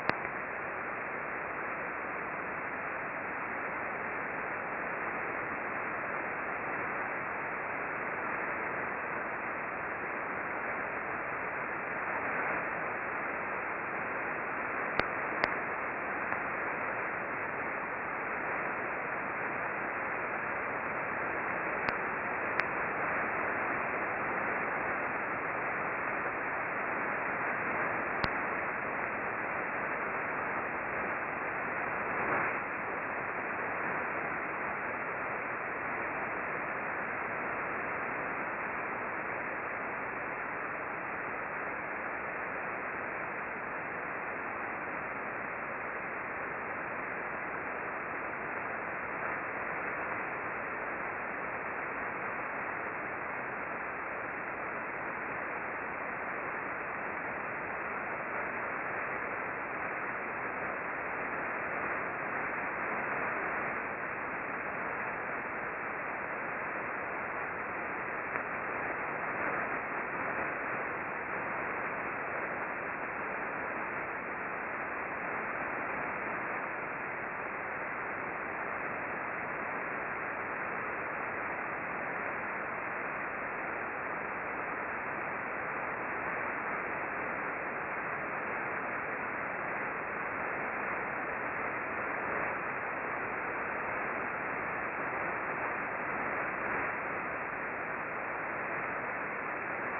We observed mostly S-bursts that shifted from receiver to receiver during the bursting periods.
Click here for a stereo sound recording of the above bursts. The random clicks heard near the beginning are RFI.